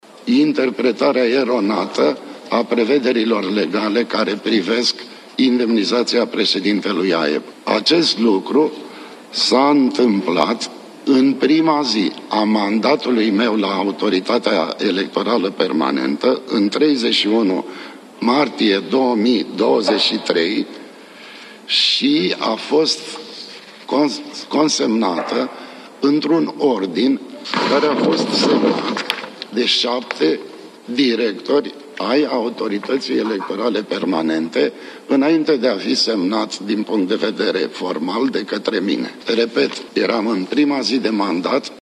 „Nu, nu mi se pare deloc normal ca președintele AEP, pentru niște alegații foarte vagi, să fie demis acum, când a început procesul electoral”, a declarat Toni Greblă vineri dimineață, aflat în fața parlamentarilor din Comisia Juridică.